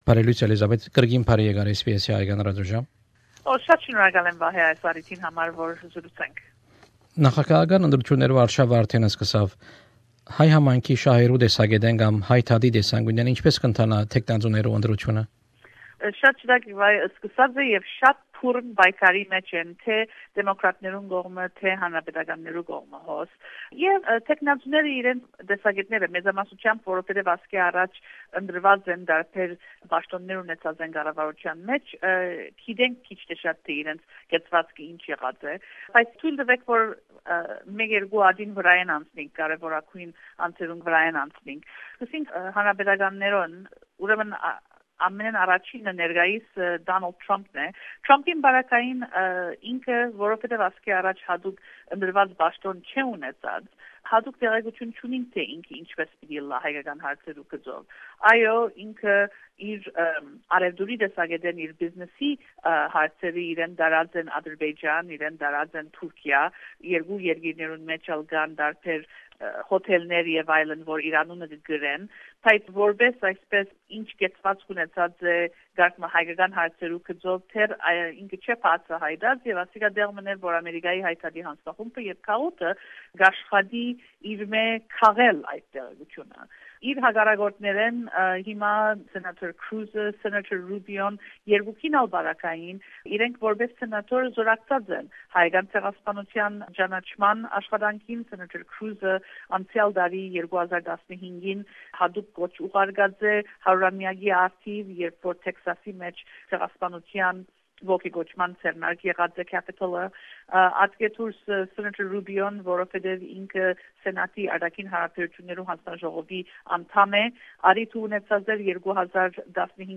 An interview with ANCA's